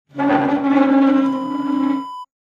Chair scraping floor sound effect .wav #4
Description: The sound of a chair scraping on the floor
Properties: 48.000 kHz 16-bit Stereo
A beep sound is embedded in the audio preview file but it is not present in the high resolution downloadable wav file.
Keywords: chair, scrape, scraping, screech, screeching, move, moving, push, pushing, pull, pulling, drag, dragging, hardwood, wooden, floor
chair-scraping-floor-preview-4.mp3